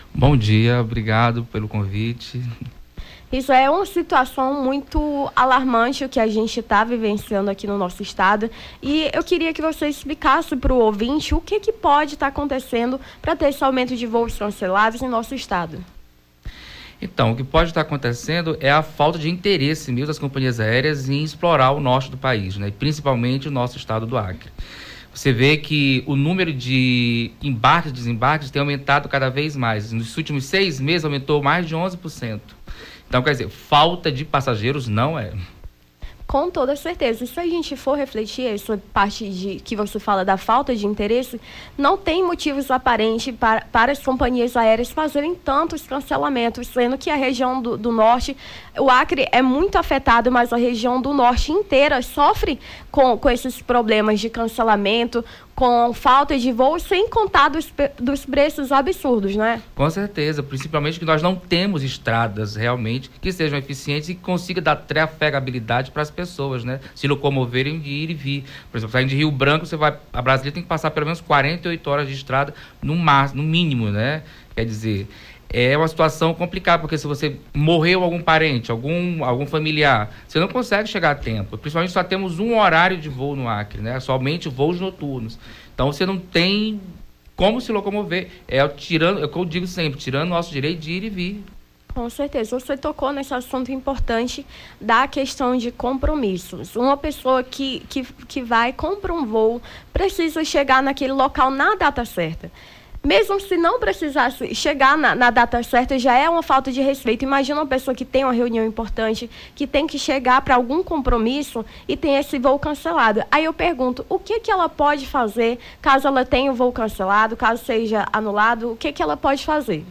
Nome do Artista - CENSURA - ENTREVISTA (CONSTANTES CANCELAMENTOS VOOS ACRE) 11-08-23.mp3